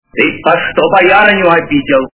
» Звуки » Из фильмов и телепередач » Из к.ф. - Ты по что боярыню обидел?
При прослушивании Из к.ф. - Ты по что боярыню обидел? качество понижено и присутствуют гудки.